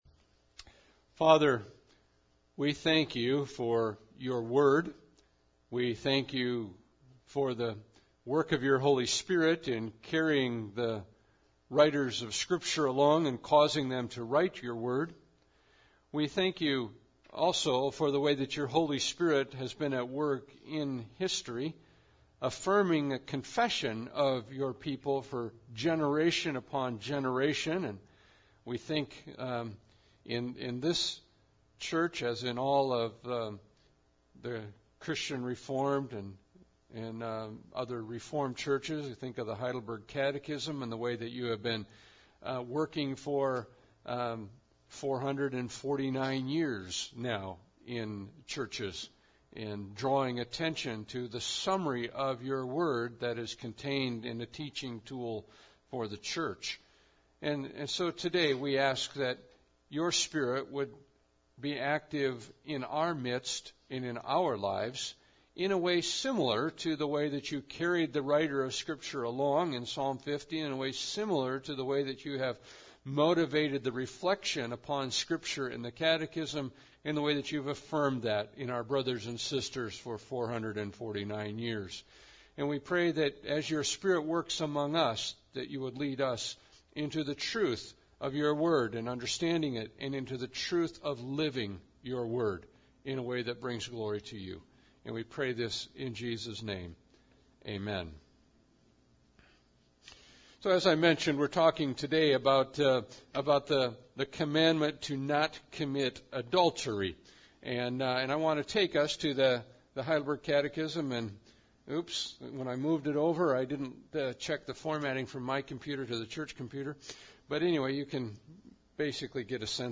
Psalms 50 Service Type: Sunday Service Bible Text